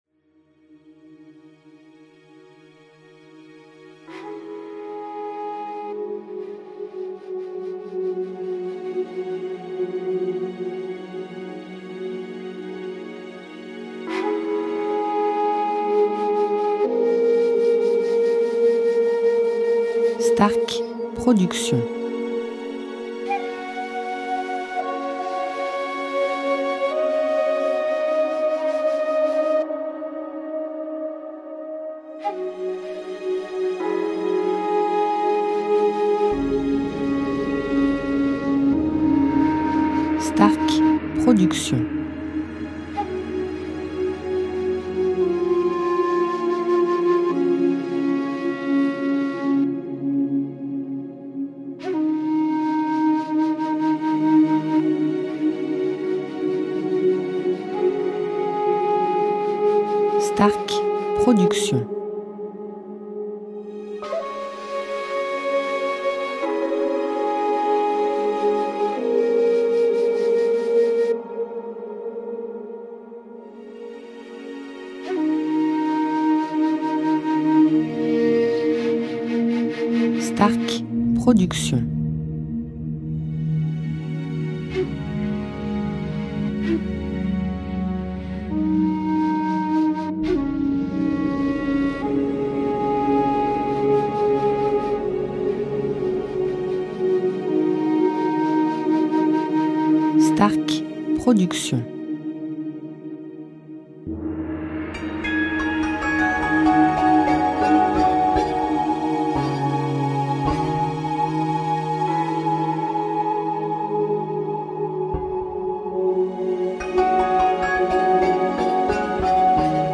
style NewAge Worldmusic durée 1 heure